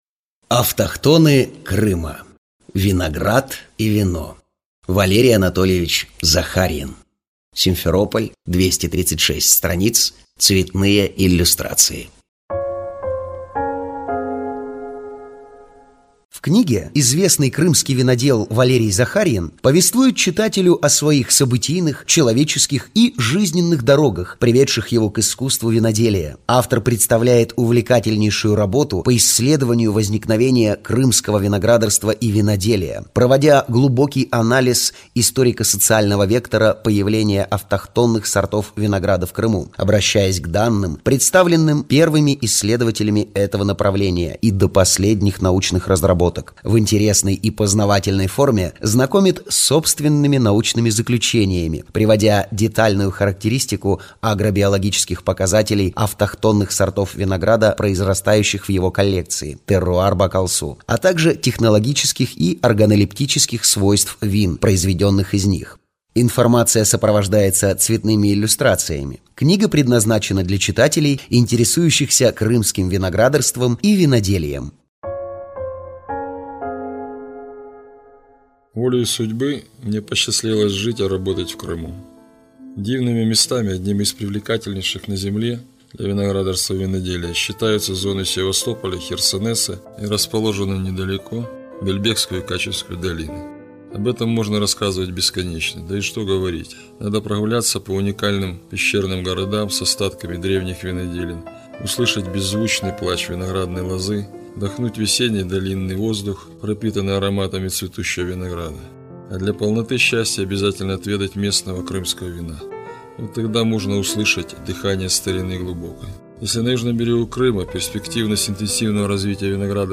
Аудиокнига Автохтоны Крыма. Виноград и вино | Библиотека аудиокниг